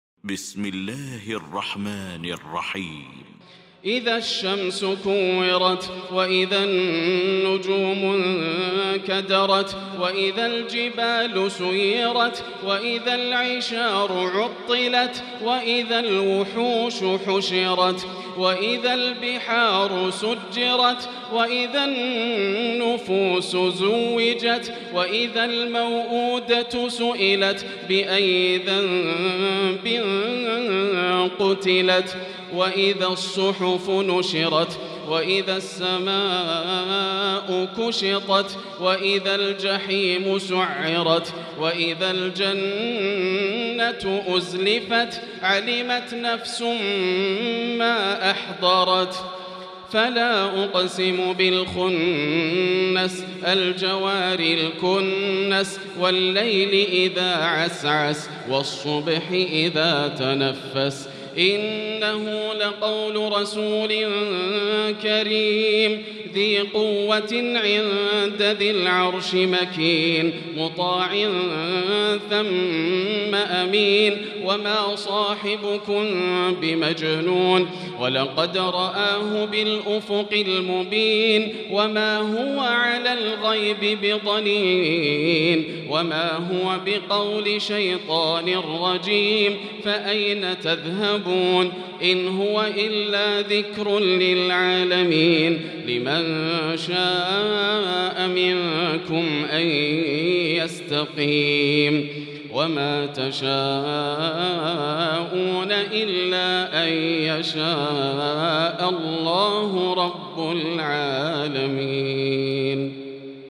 المكان: المسجد الحرام الشيخ: فضيلة الشيخ ياسر الدوسري فضيلة الشيخ ياسر الدوسري التكوير